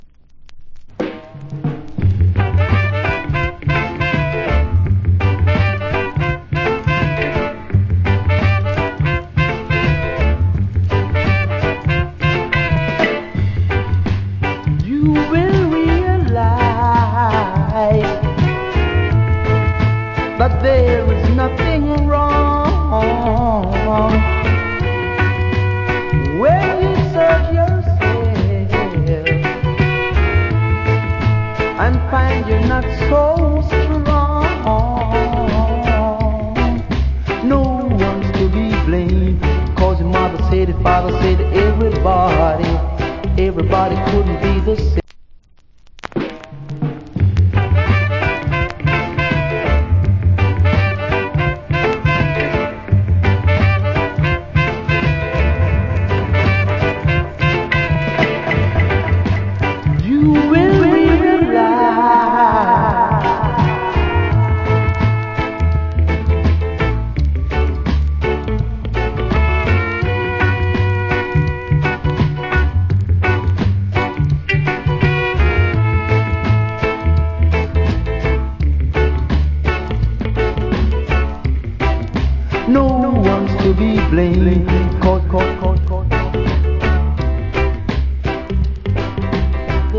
Great Rock Steady Vocal.